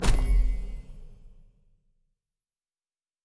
Special Click 08.wav